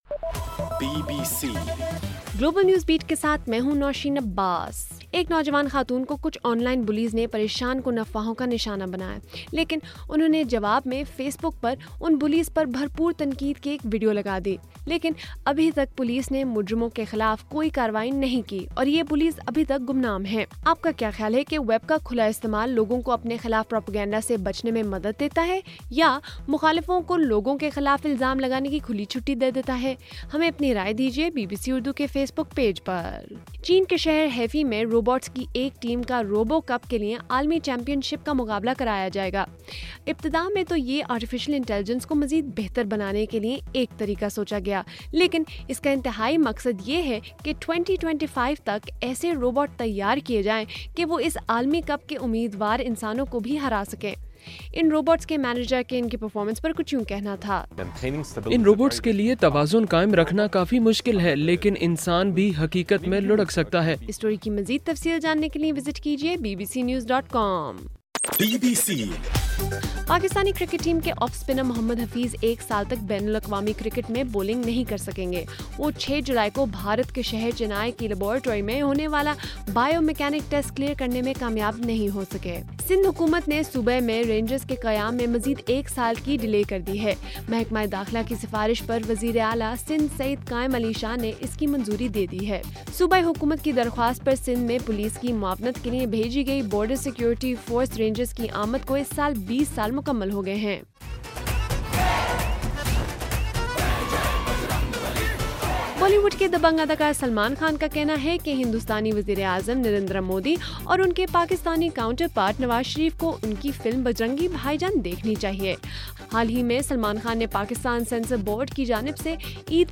جولائی 18: صبح1 بجے کا گلوبل نیوز بیٹ بُلیٹن